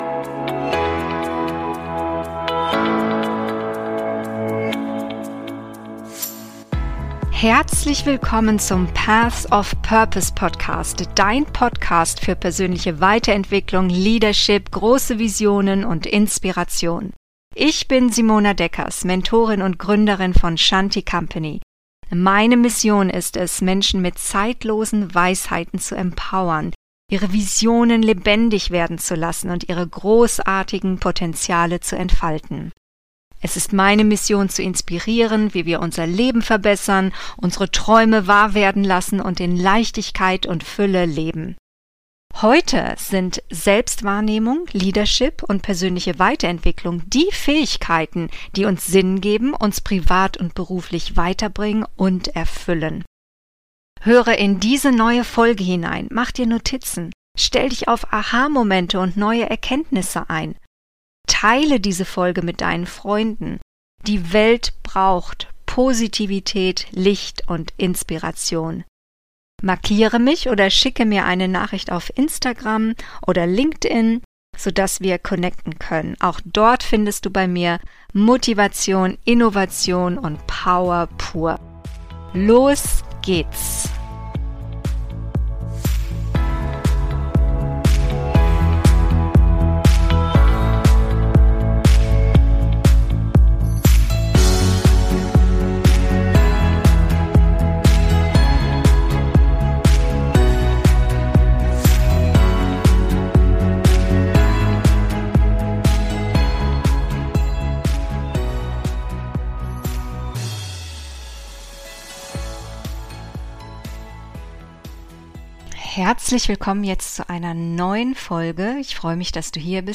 Was kommt nach dem Erfolg? - Solofolge